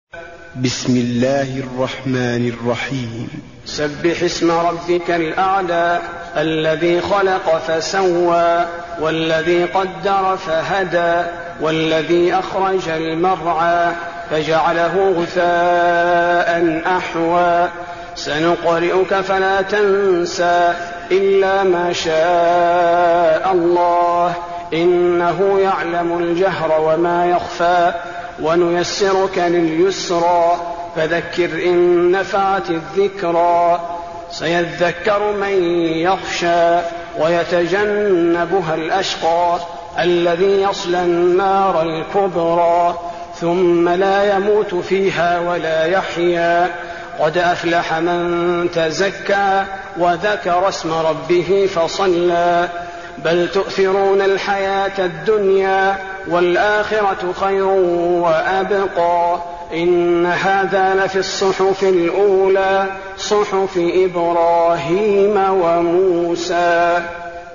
المكان: المسجد النبوي الأعلى